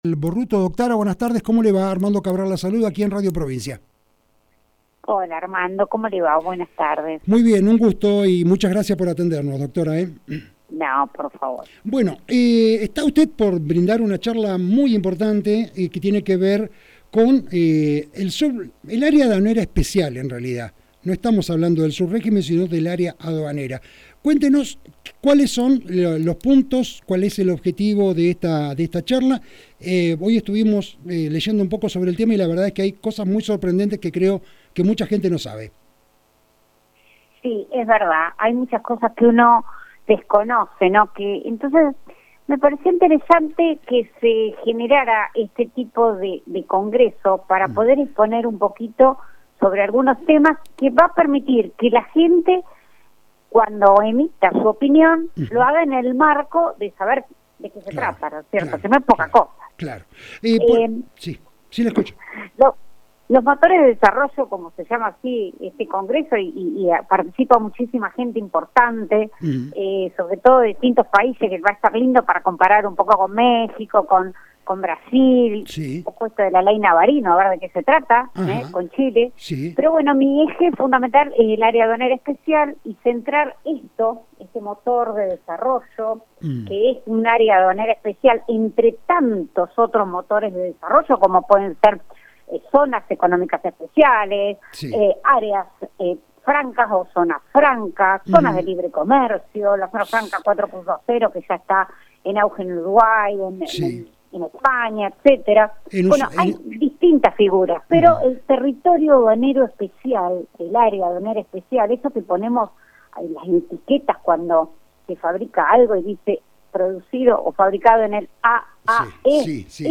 Nota con la Dra Mariel Borruto, Juez Federal de Rio Grande
Fuente Radio Provincia, Resumen Económico.